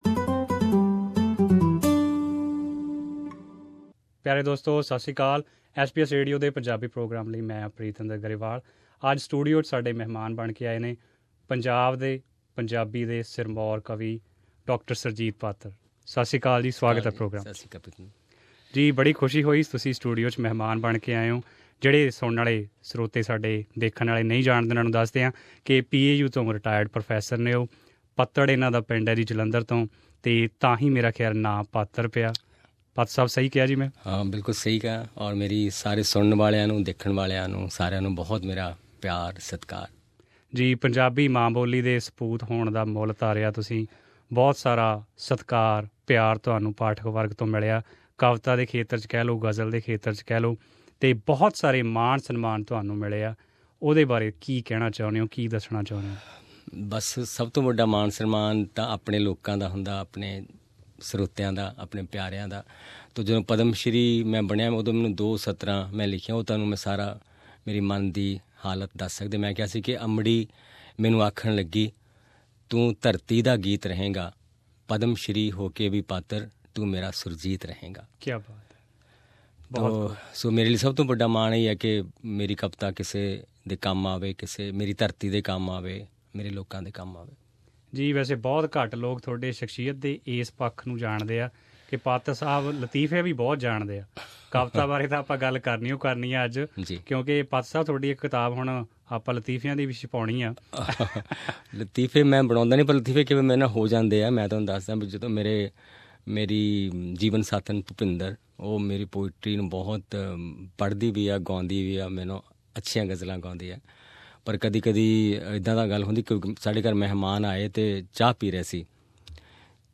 Celebrated Punjabi poet and writer Surjit Patar was our studio guest at Melbourne.